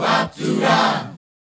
Bah Boo Dah 152-A.wav